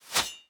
Sword Attack 2.ogg